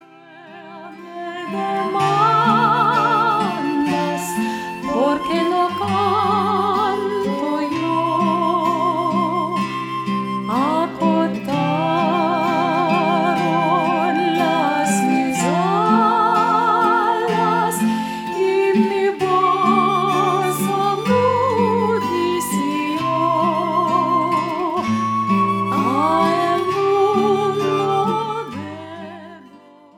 (Folk)